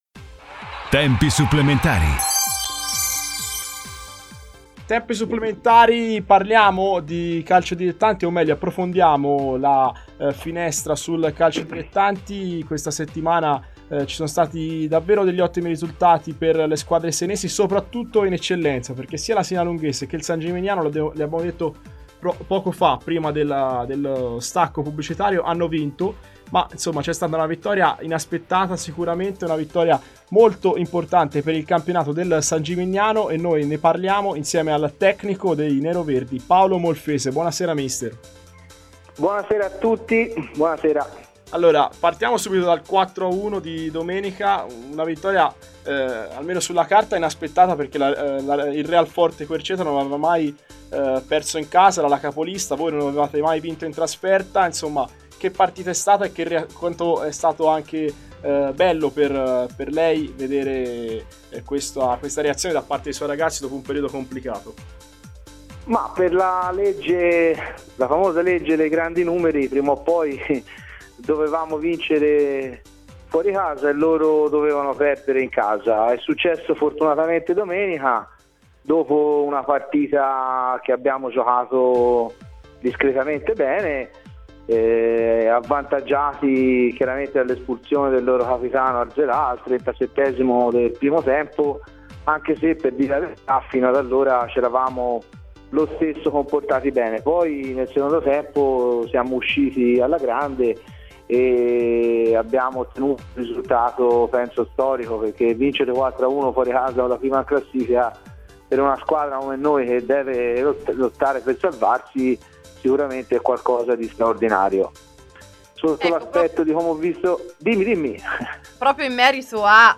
Terzo appuntamento questo pomeriggio con “SportARE – Tutte le coniugazioni dello sport senese”, la nuova trasmissione dedicata allo sport in onda ogni giovedì dalle 18 alle 20 sulle frequenze di Antenna Radio Esse.